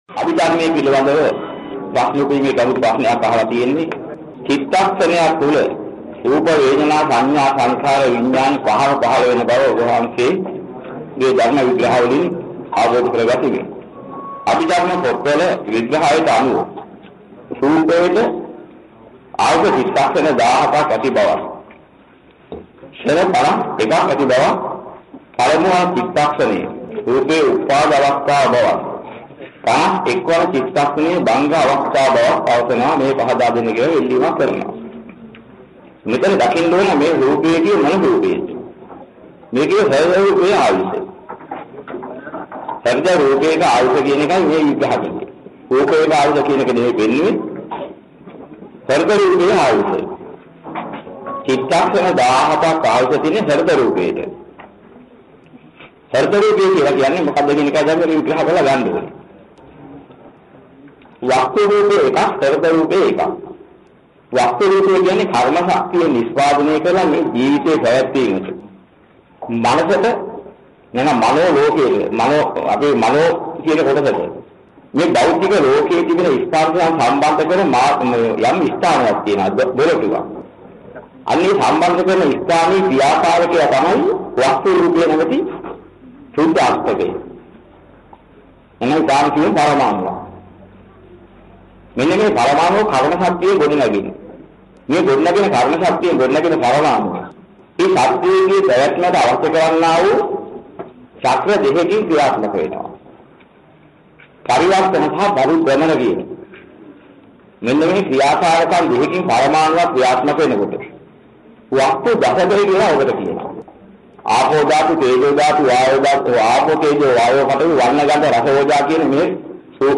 අභිධර්ම දේශනා 1